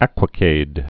(ăkwə-kād, äkwə-)